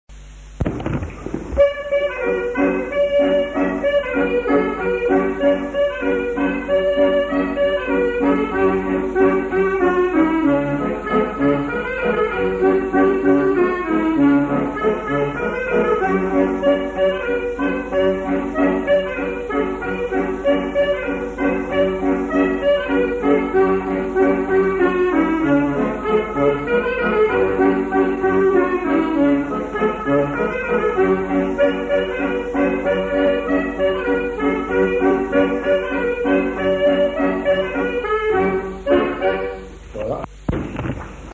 Airs à danser interprétés à l'accordéon diatonique
enquêtes sonores
Scottish